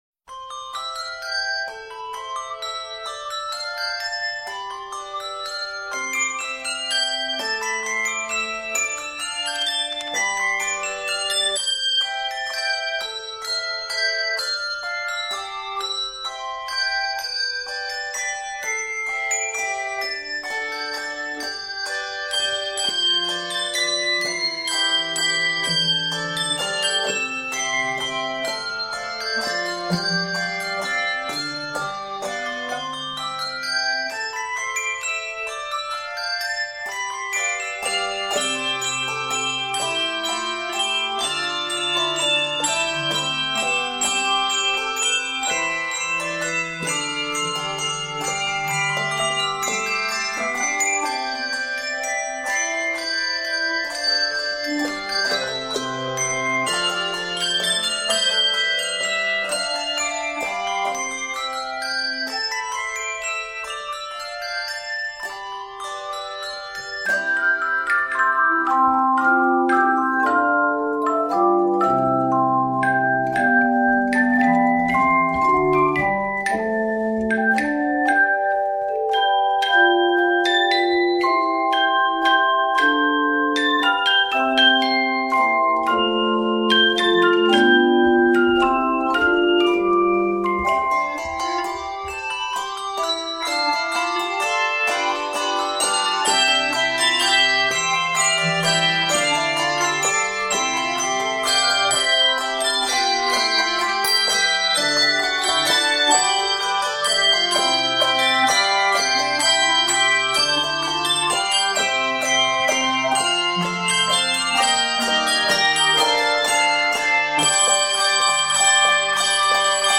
Lilting and accessible